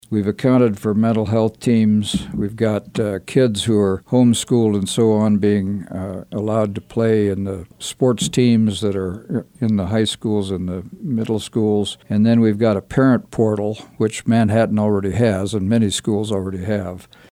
Rep. Carlin and Rep. Dodson appeared on KMAN’s In Focus Friday to recap the busy week in the Kansas Legislature.